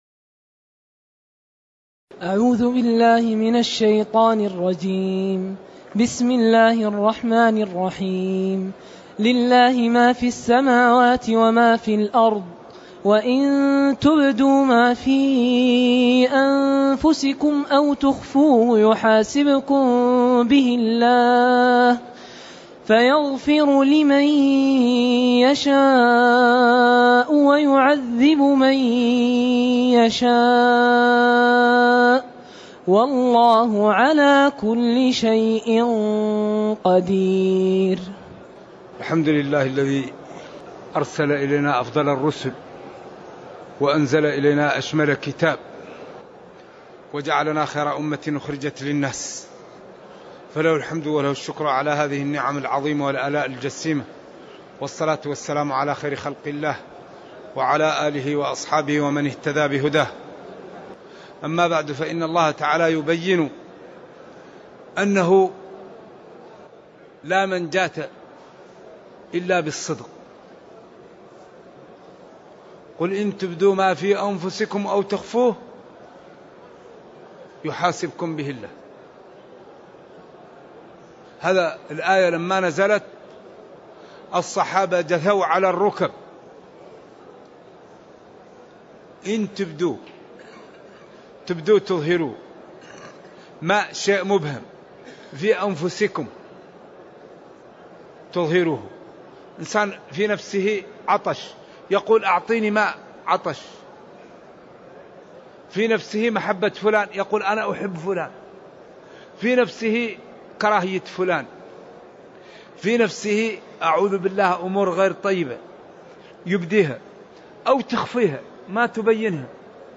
تاريخ النشر ٤ ذو الحجة ١٤٢٨ هـ المكان: المسجد النبوي الشيخ